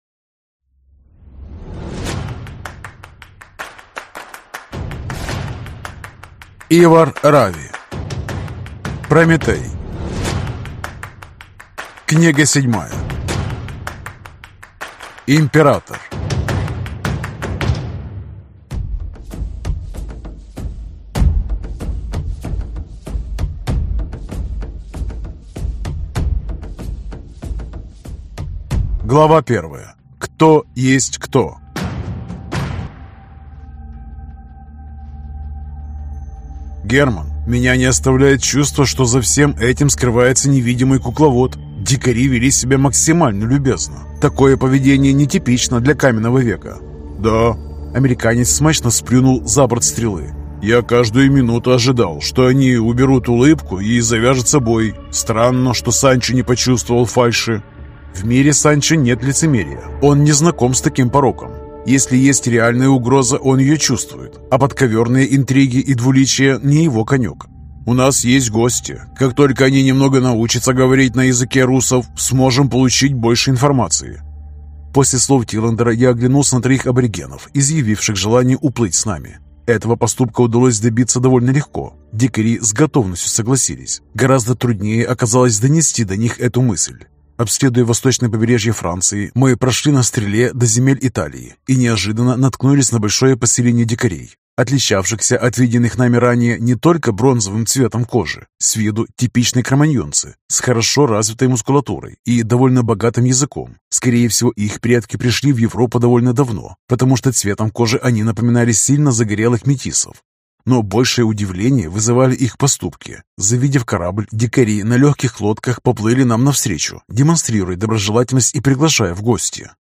Аудиокнига Император | Библиотека аудиокниг